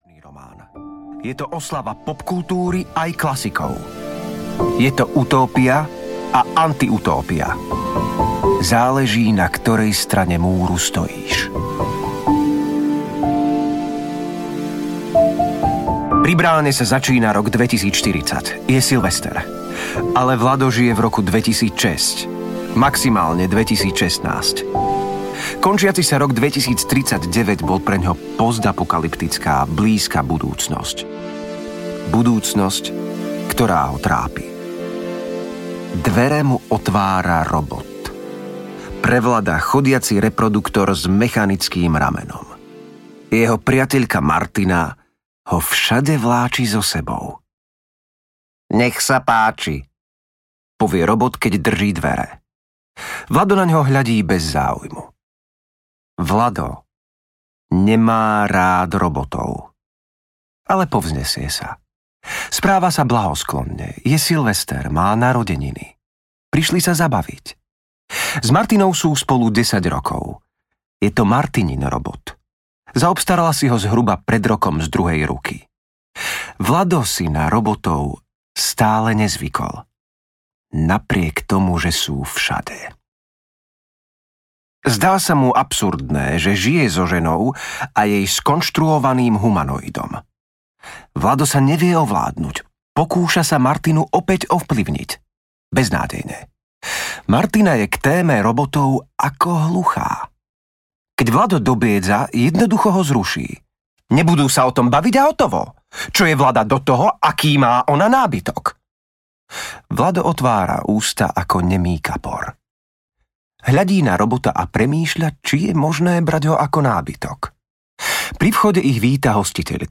Veľká budúcnosť audiokniha
Ukázka z knihy
velka-buducnost-audiokniha